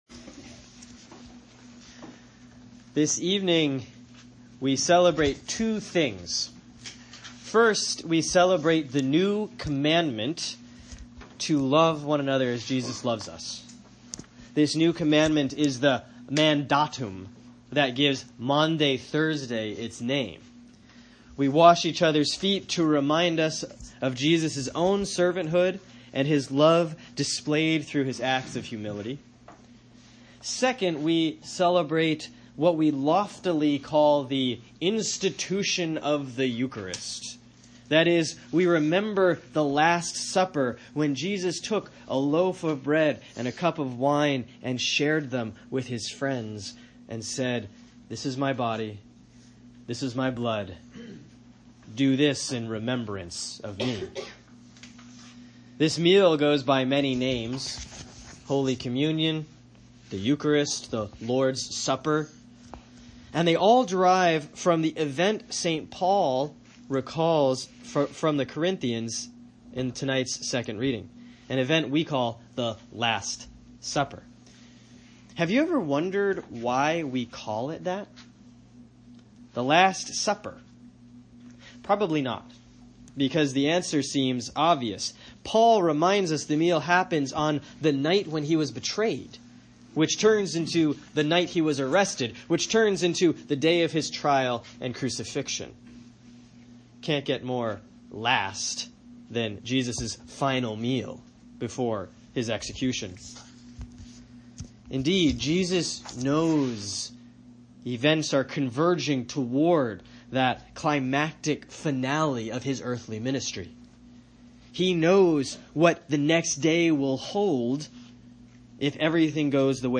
Sermon for Maundy Thursday, April 13, 2017 || 1 Corinthians 11:23-26